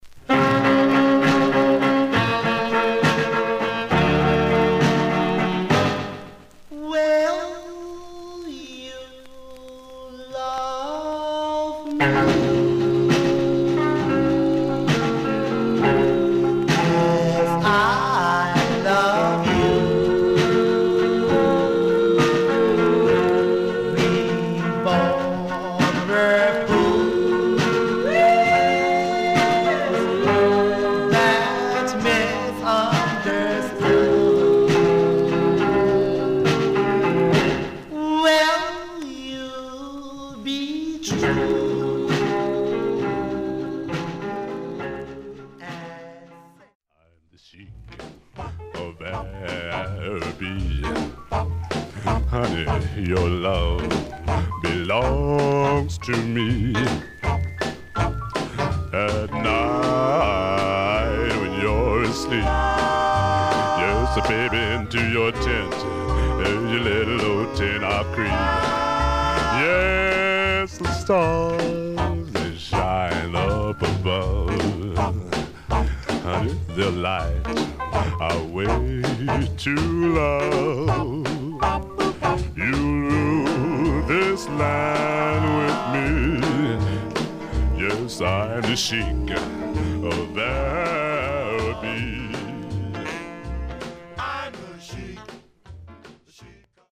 Some surface noise/wear Stereo/mono Mono
Male Black Group Condition